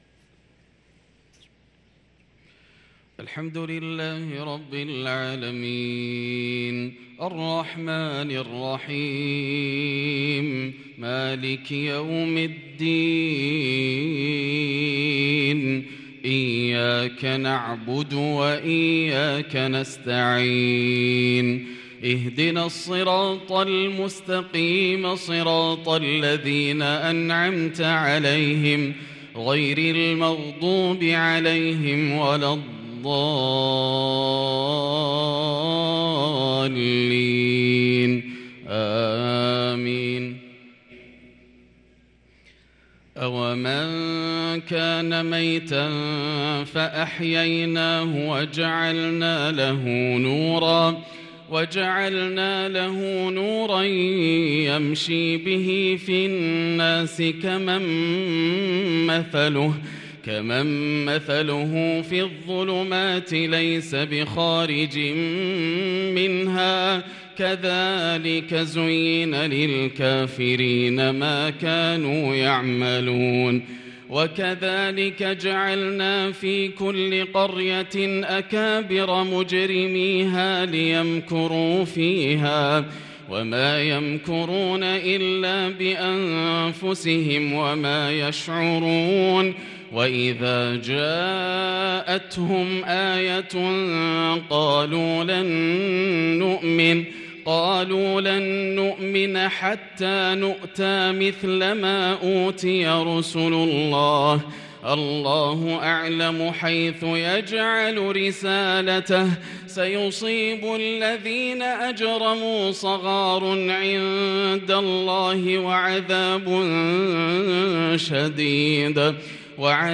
صلاة الفجر للقارئ ياسر الدوسري 26 ذو الحجة 1443 هـ
تِلَاوَات الْحَرَمَيْن .